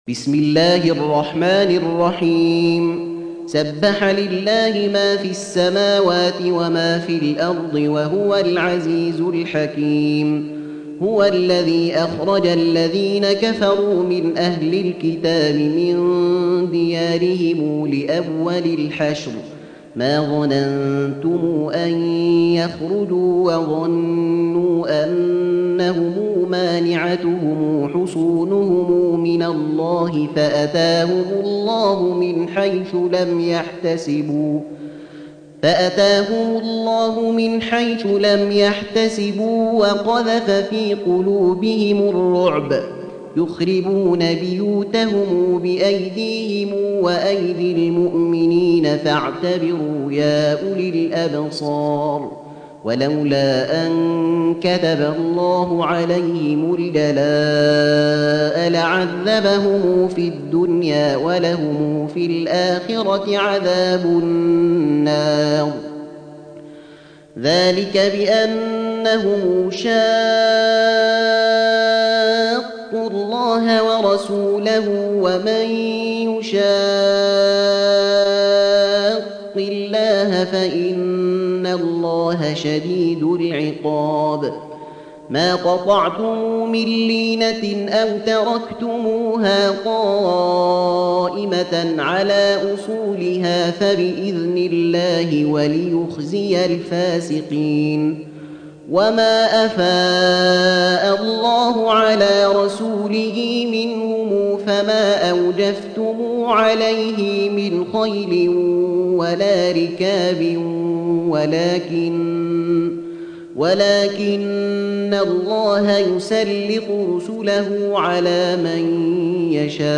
Surah Sequence تتابع السورة Download Surah حمّل السورة Reciting Murattalah Audio for 59. Surah Al-Hashr سورة الحشر N.B *Surah Includes Al-Basmalah Reciters Sequents تتابع التلاوات Reciters Repeats تكرار التلاوات